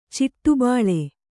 ♪ ciṭṭu bāḷe